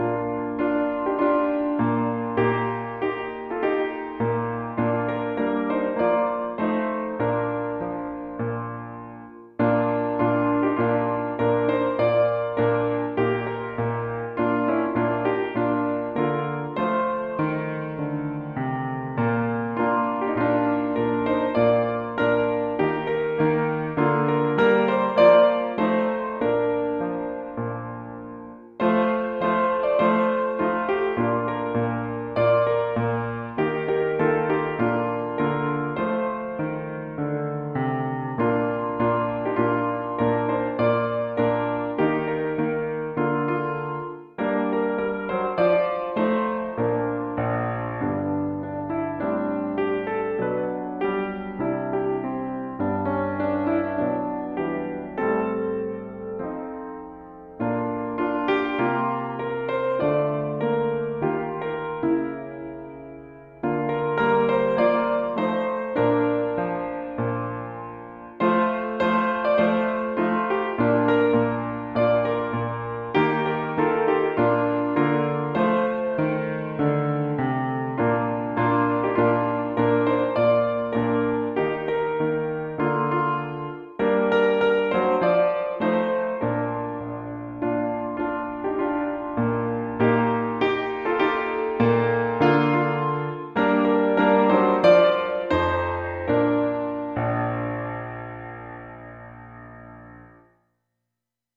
Easy intermediate arrangement
hymn tune